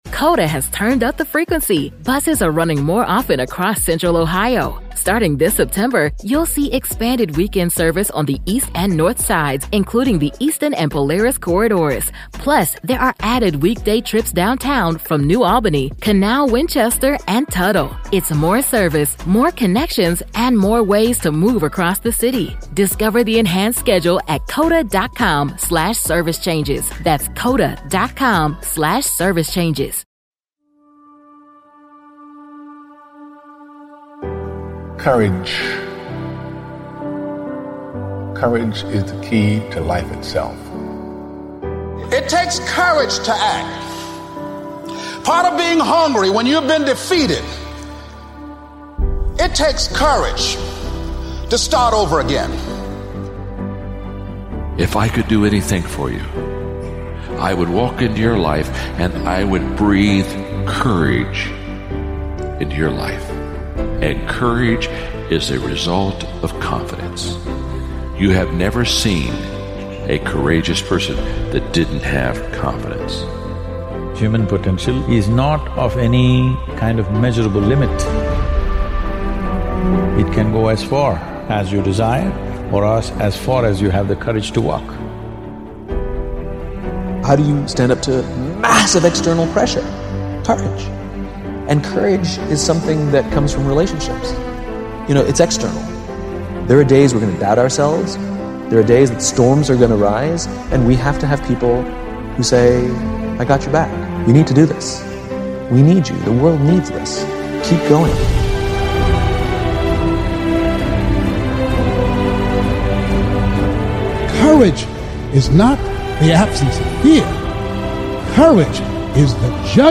Speakers: Morgan Freeman